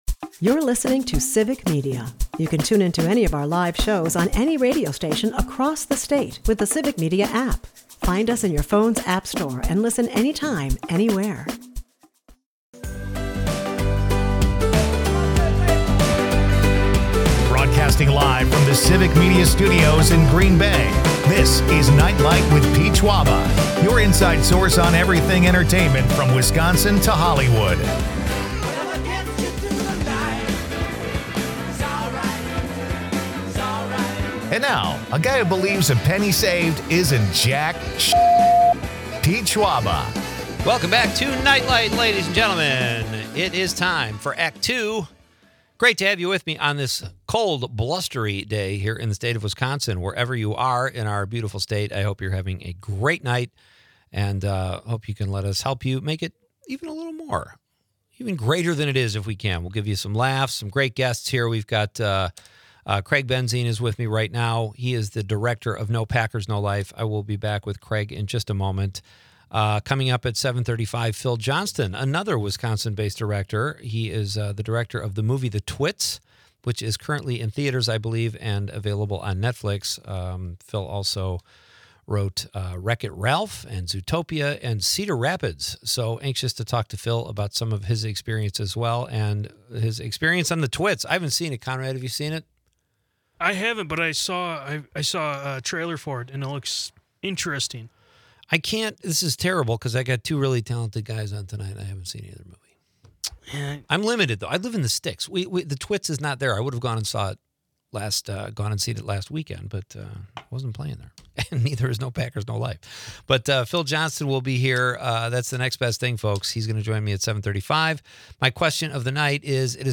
from Civic Media Studios in Green Bay